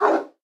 pdog_idle_4.ogg